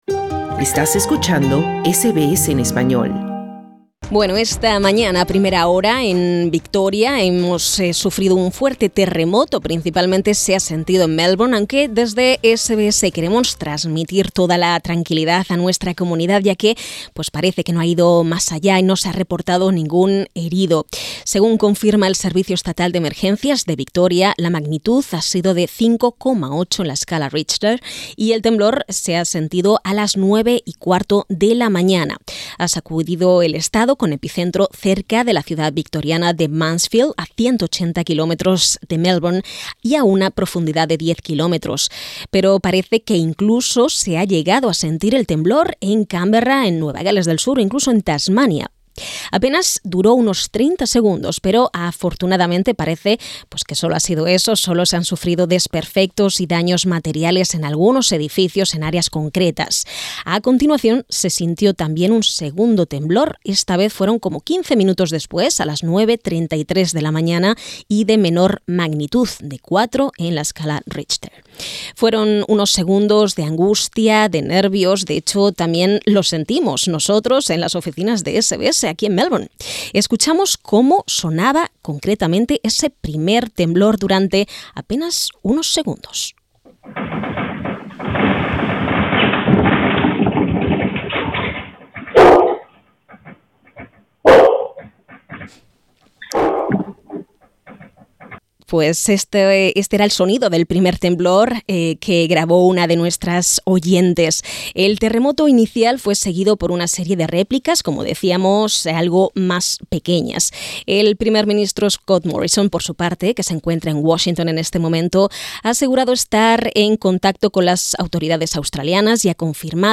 Afectados por el terremoto relatan emocionados el susto que vivieron durante los segundos del temblor.